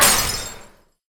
poly_explosion_skull.wav